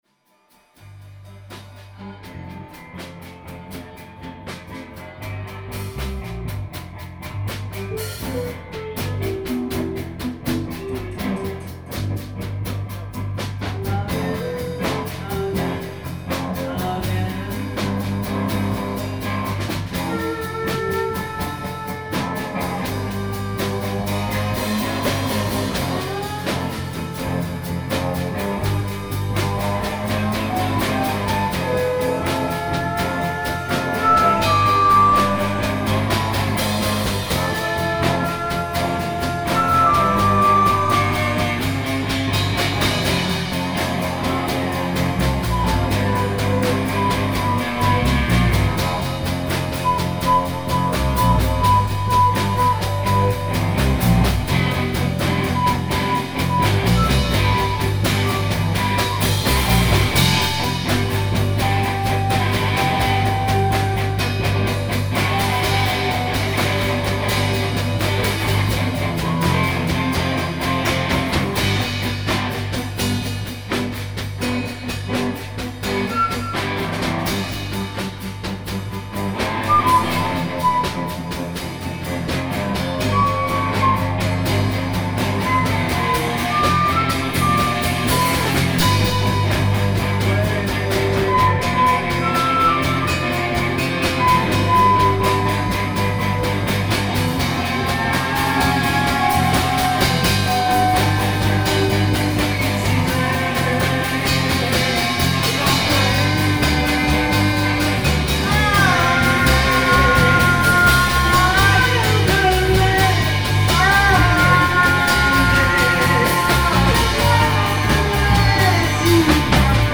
ALL MUSIC IS IMPROVISED ON SITE
guitar/voice
keys/moog
flute
drums
bass/voice